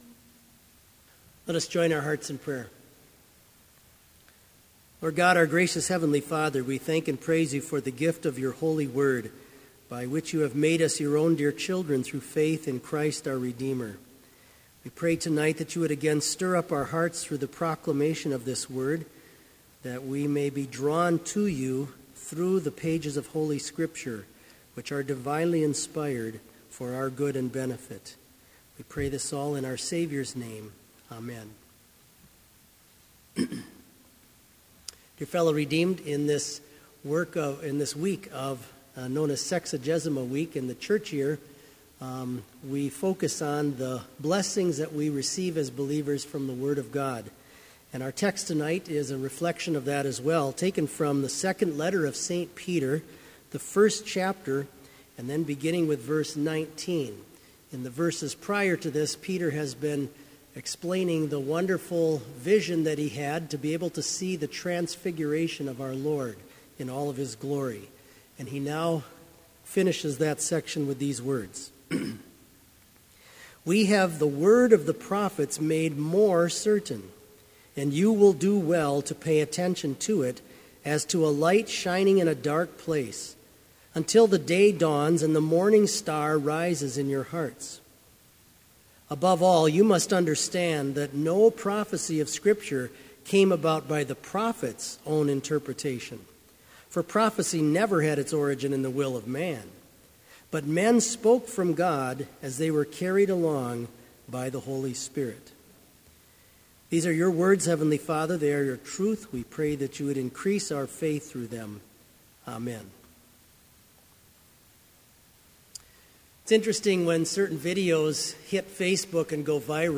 Sermon audio for Evening Vespers - February 3, 2016